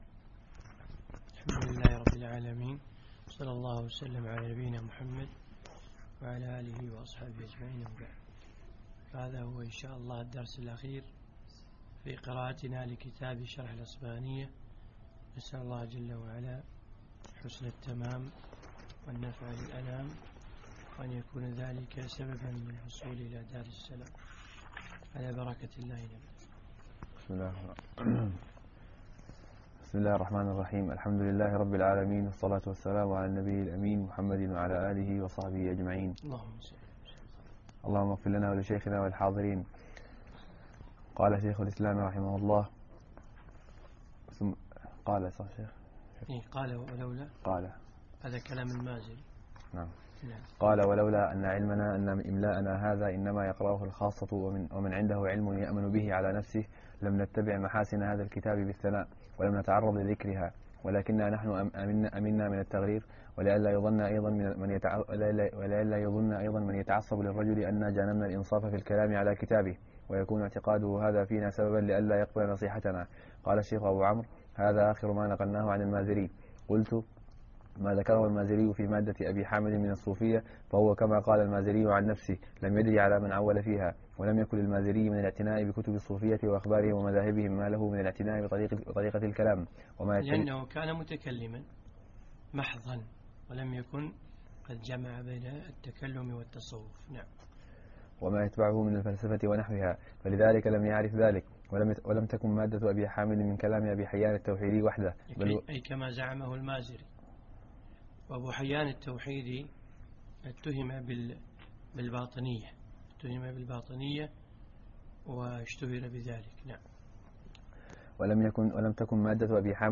من دروس الشيخ في دولة الإمارات